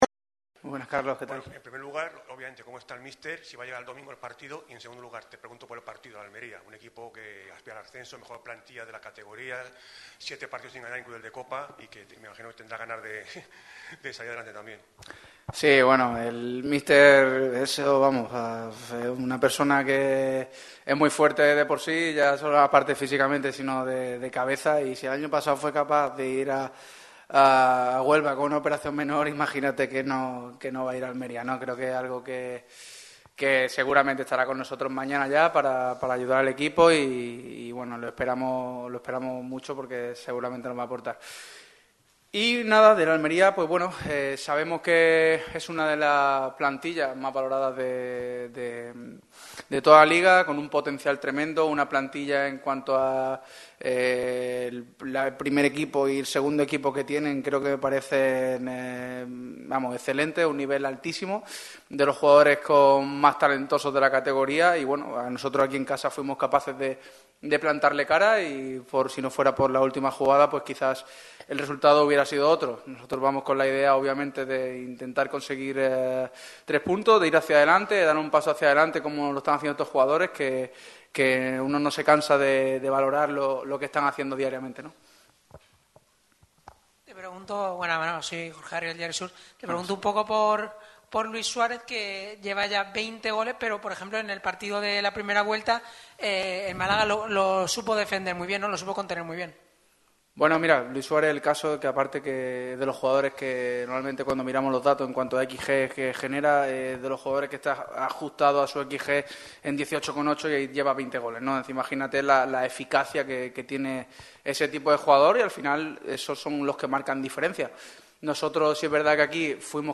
comparece en sala de prensa